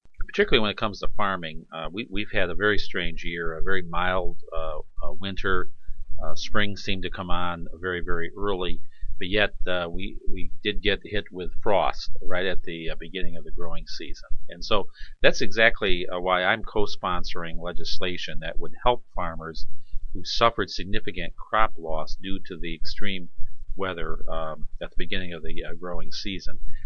here to here a brief statement from Sen. Seward about the “Family Farmers and Apple Growers Relief Act.”